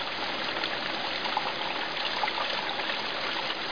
1 channel
Fountain1.mp3